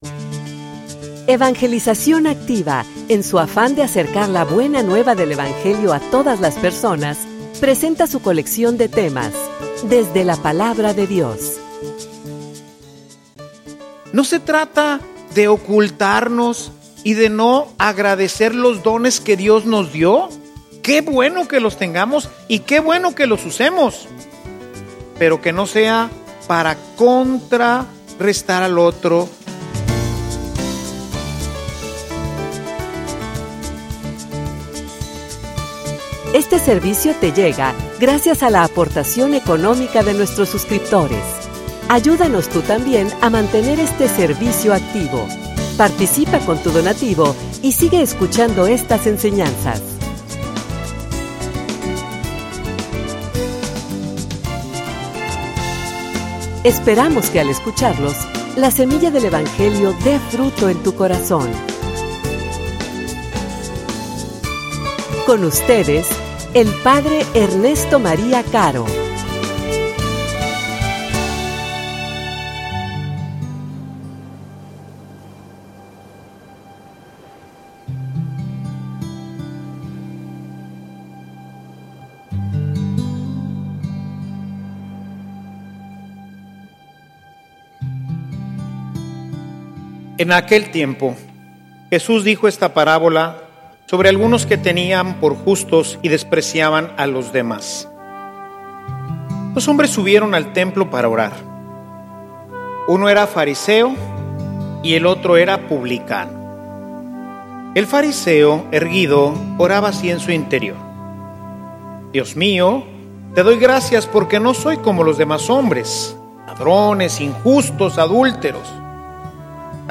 homilia_La_mentalidad_farisea.mp3